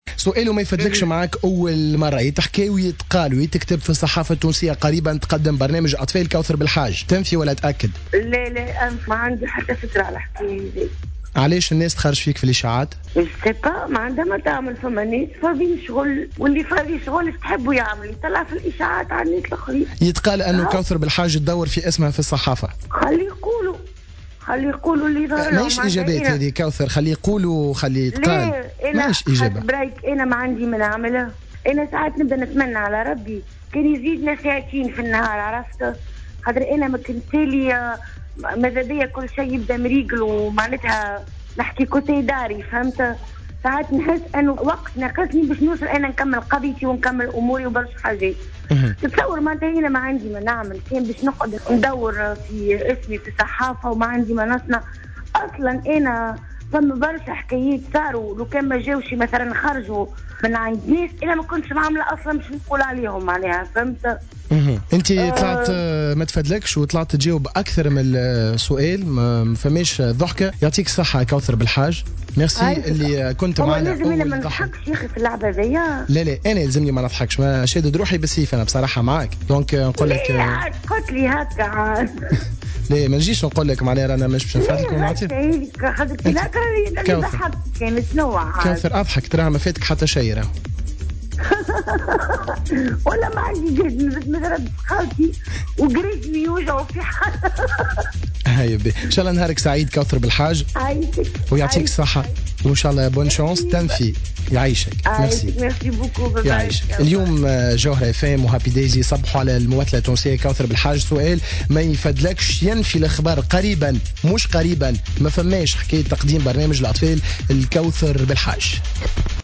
نفت الممثلة كوثر بلحاج في مداخلة هاتفية في برنامج happy days لليوم الاثنين 16 فيفري 2015 تقديمها قريبا لبرنامج للأطفال.